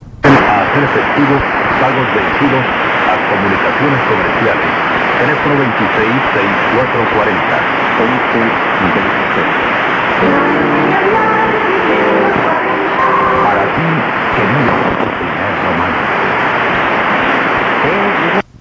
All recordings were made in Santa Barbara, Honduras (SB) using a Yaesu FRG-7 receiver, except for the one marked Danli, which was recorded in Danli, Honduras using an ICF-7600 and the internal loop.